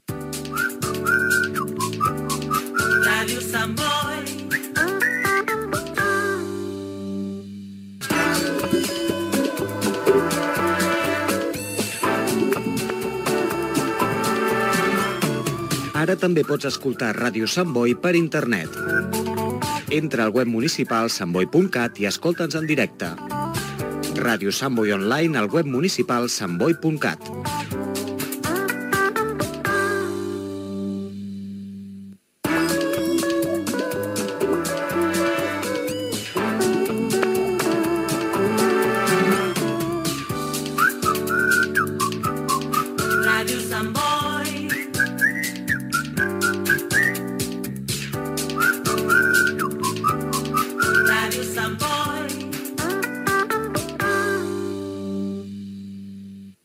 Indicatiu de la ràdio